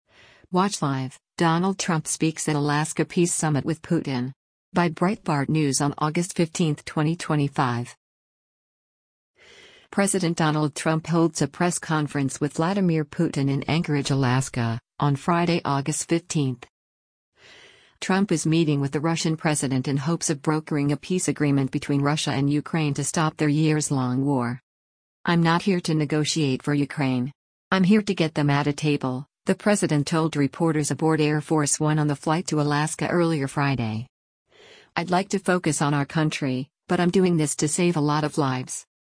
President Donald Trump holds a press conference with Vladimir Putin in Anchorage, Alaska, on Friday, August 15.